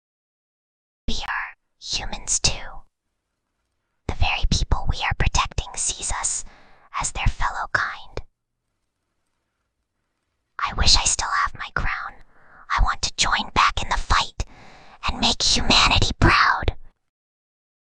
File:Whispering Girl 37.mp3
Whispering_Girl_37.mp3